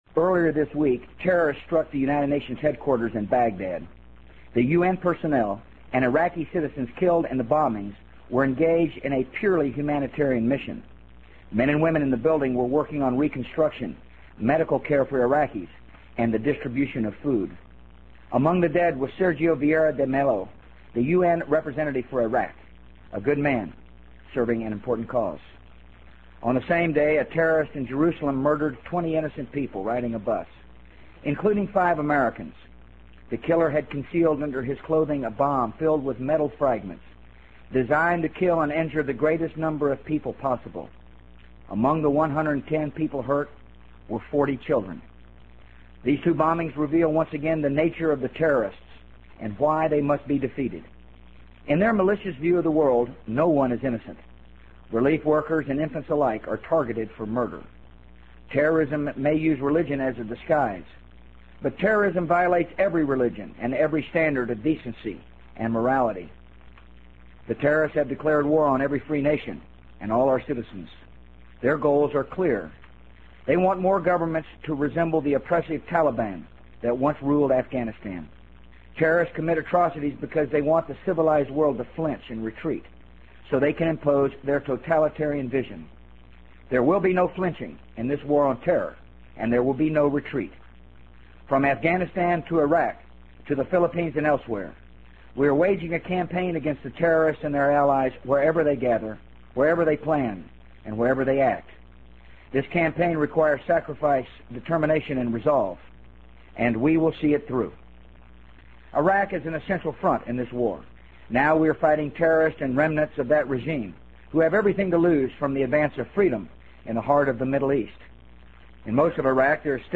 【美国总统George W. Bush电台演讲】2003-08-23 听力文件下载—在线英语听力室